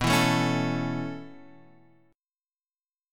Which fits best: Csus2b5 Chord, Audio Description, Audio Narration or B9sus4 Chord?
B9sus4 Chord